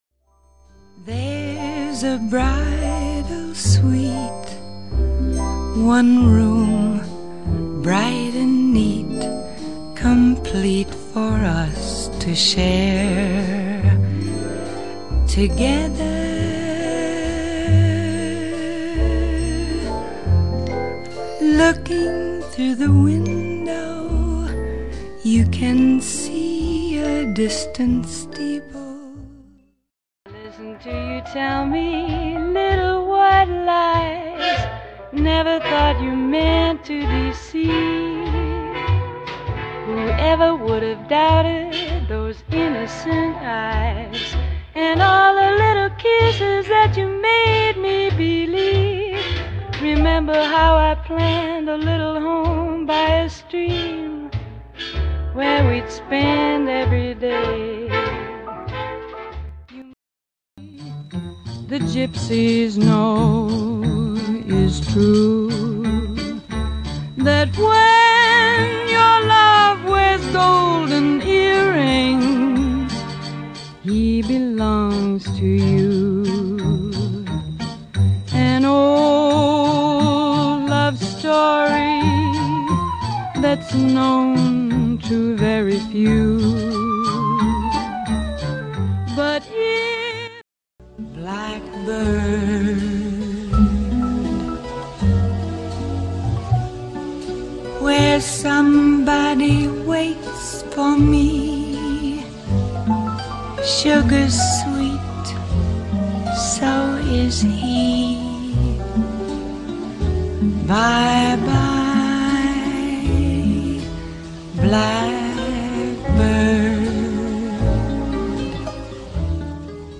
She was a fantasic pop and jazz singer.
she did swing, jazz, pop, blues, ballads and Latin.
But she was not a belter. Her style was understated.